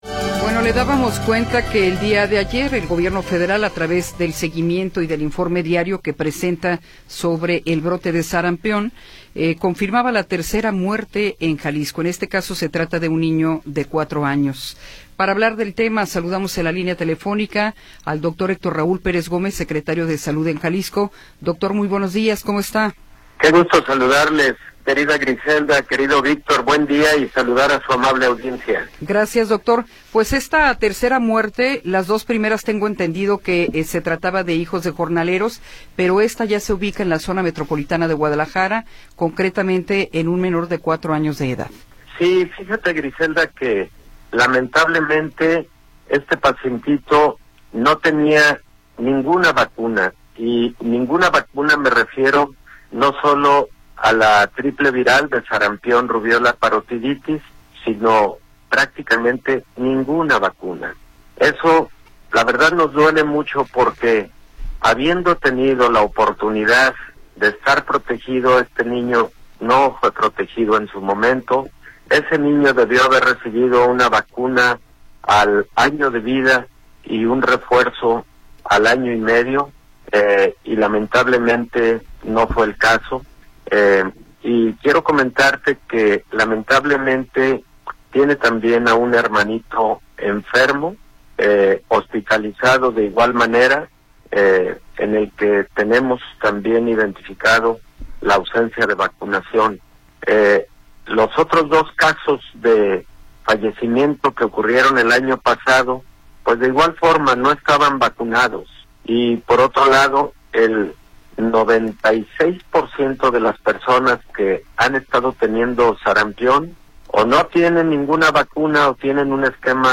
Entrevista con el Dr. Héctor Raúl Pérez Gómez
El Dr. Héctor Raúl Pérez Gómez, Secretario de Salud en Jalisco, nos habla sobre la muerte de un niño de 4 años por sarampión.